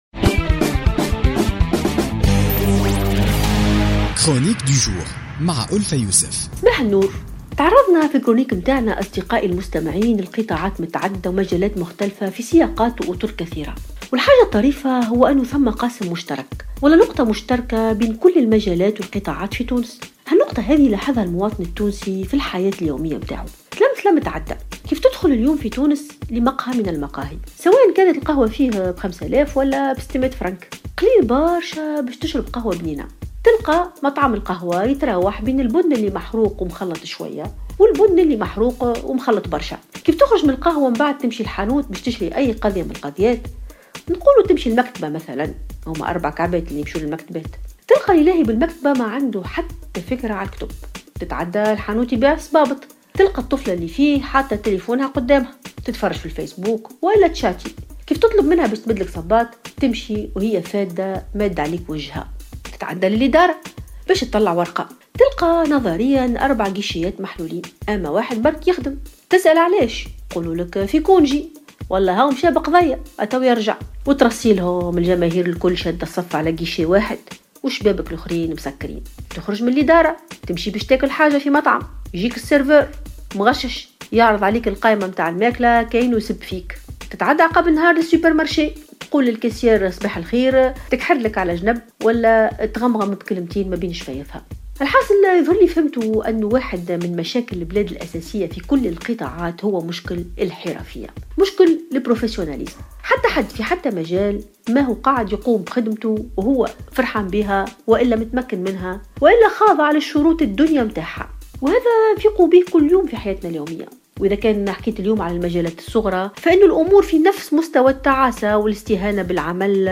تعرضت ألفة يوسف في افتتاحية اليوم الجمعة إلى مشكل غياب المهنية في كل القطاعات والذي يعد المشكل الأساسي ضمن كل المشاكل التي تواجهها تونس اليوم.